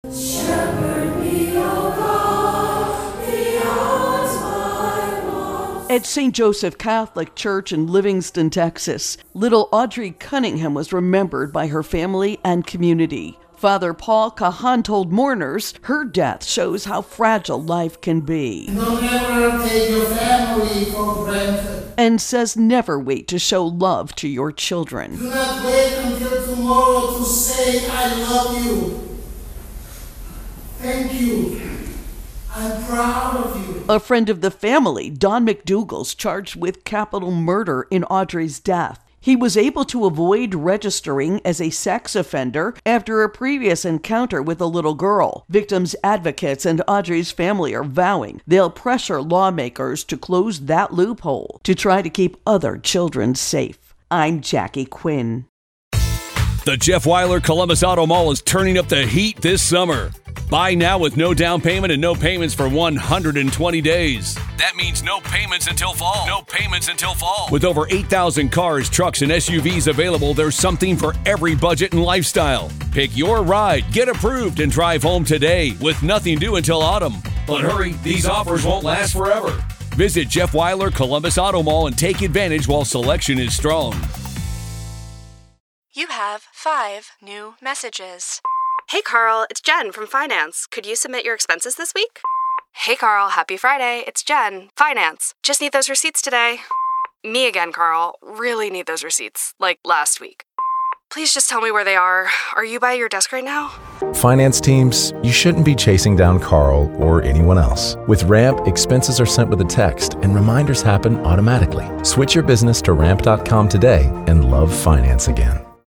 reports on the funeral for an 11-year-old Texas girl who was killed last month.